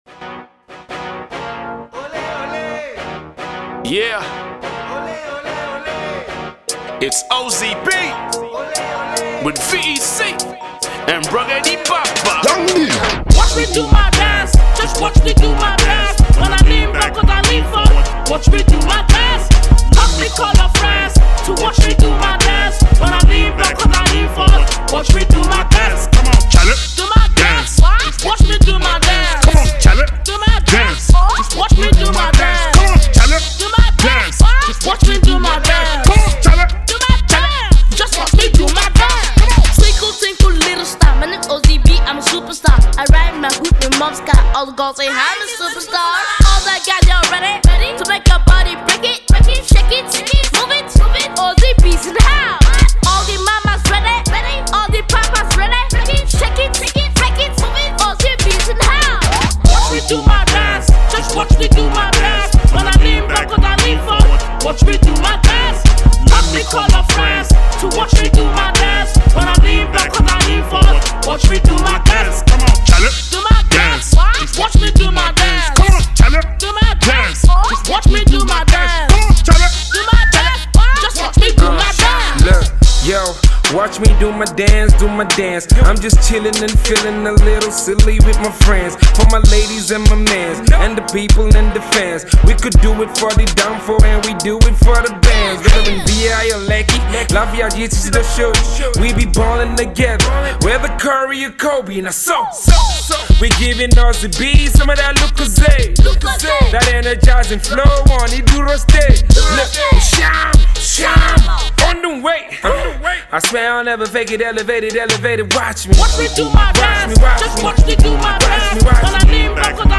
sublime Afro hip-hop song